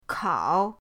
kao3.mp3